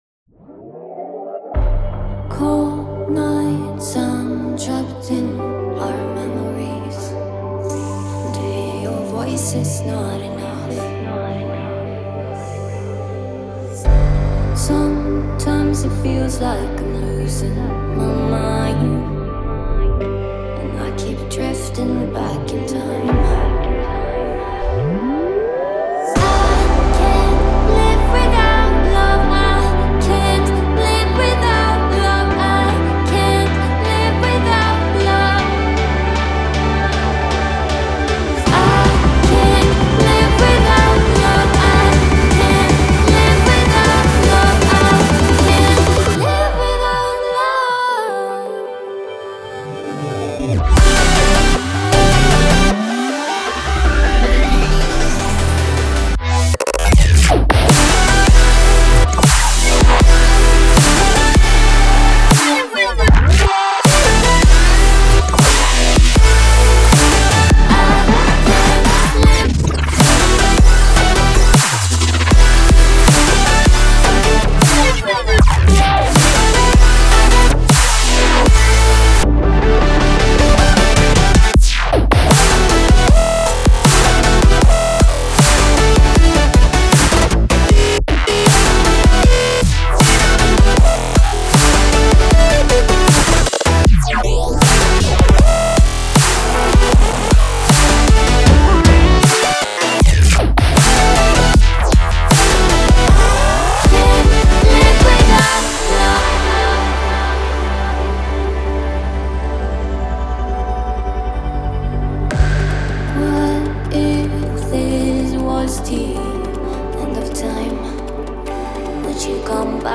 a melodic yet gritty dubstep track
featuring singer-songwriter